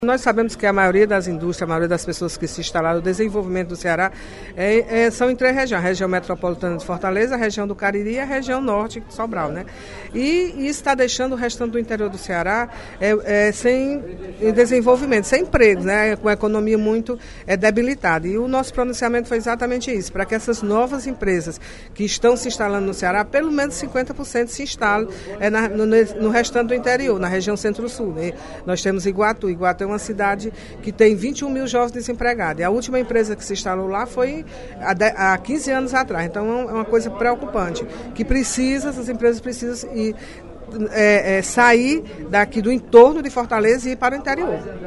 A deputada Mirian Sobreira (PSB) fez pronunciamento nesta quarta-feira (15/02) para pedir a união das lideranças políticas que representam a região Centro Sul do Ceará, no sentido de reivindicar a implantação de investimentos nos municípios daquela área do Estado.